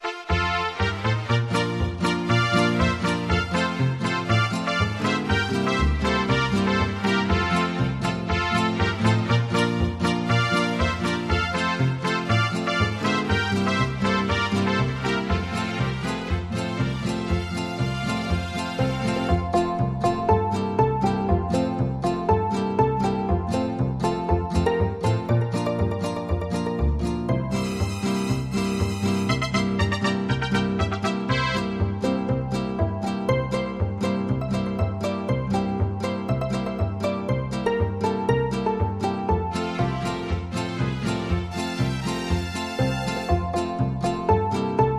MIDI · Karaoke